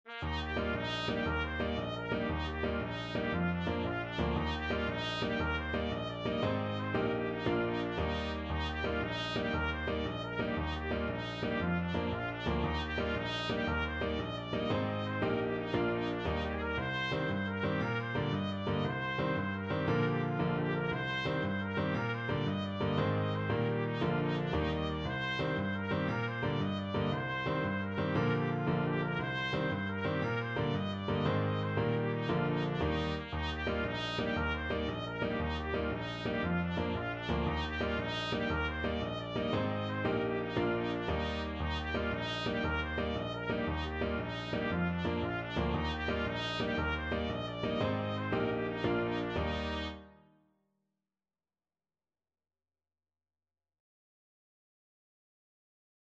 Trumpet
6/8 (View more 6/8 Music)
Eb major (Sounding Pitch) F major (Trumpet in Bb) (View more Eb major Music for Trumpet )
With energy .=c.116
Irish